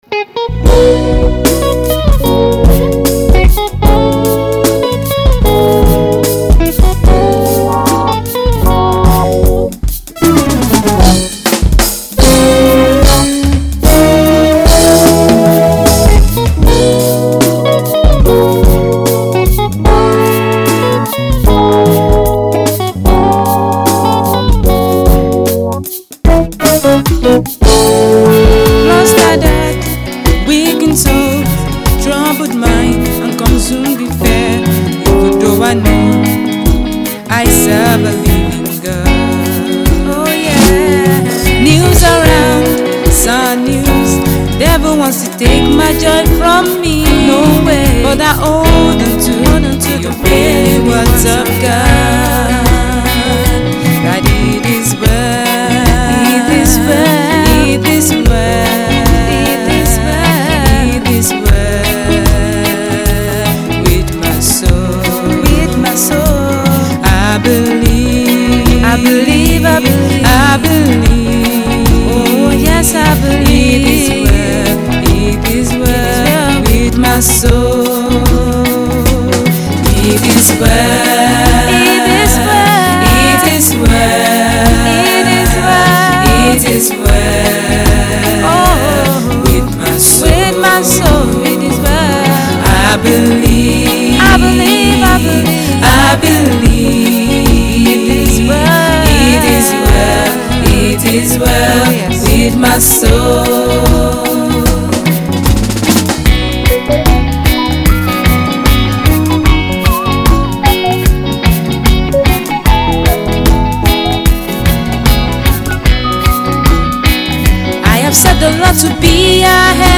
soul lifting single
is a versatile gospel singer an